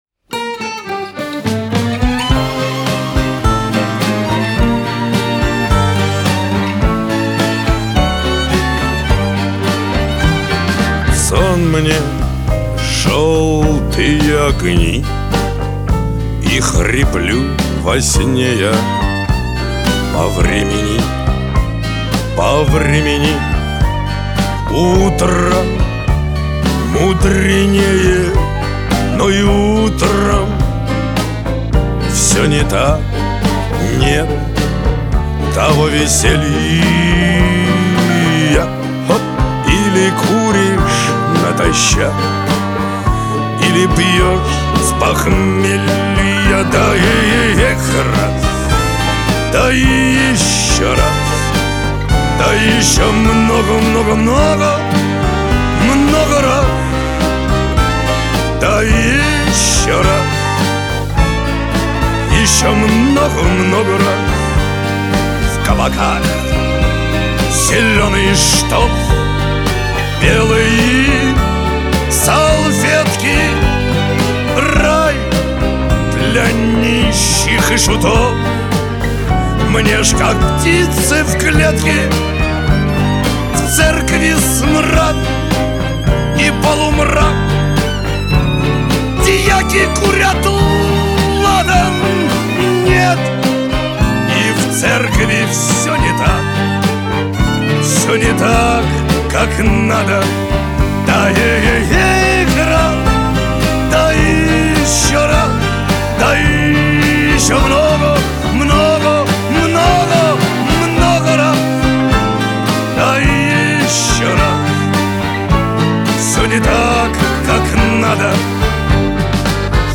Кавер-версия
диско
Шансон